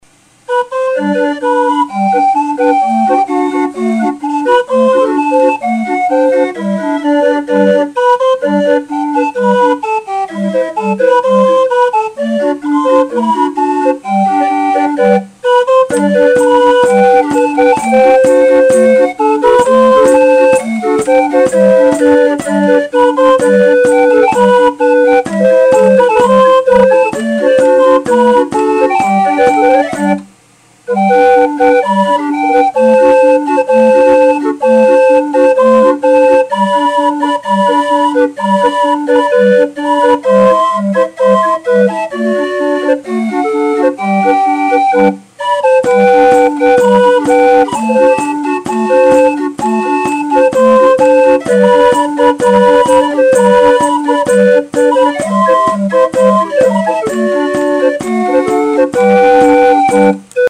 With ornate proceniums, the sound produced by the pipes is sweet and conducive to a circus atmosphere.
It contains 39 pipes and has a 20 note range plus percussion with a tambourine.
bandorgan.mp3